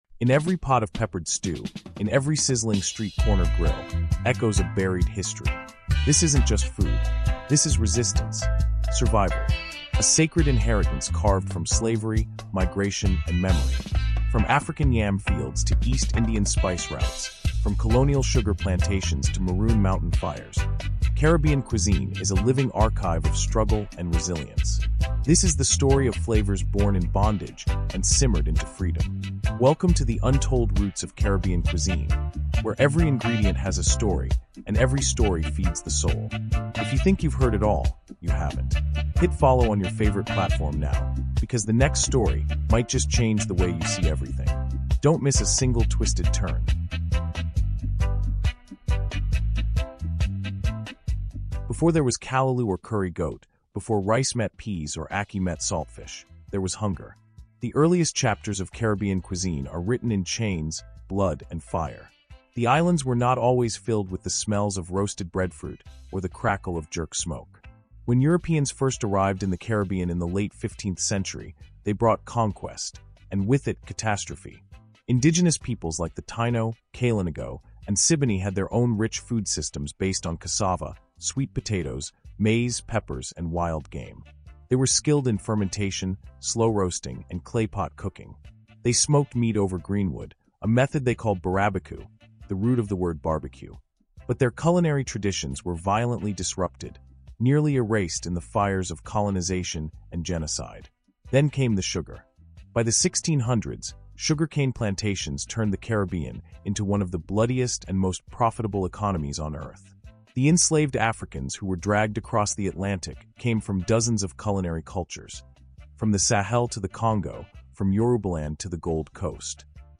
Exploring the Roots of Caribbean Cuisine is a six-part cinematic journey told by history experts, chefs, and storytellers. From the Maroon communities of Jamaica to Trinidad’s Carnival streets, we dive into the origin stories behind jerk chicken, curry goat, callaloo, roti, and pelau — each dish a legacy of resistance and migration.